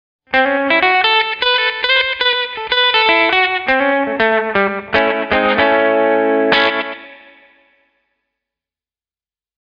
Tältä Flashback X4:n efektityypit kuulostavat:
Slap
slap1.mp3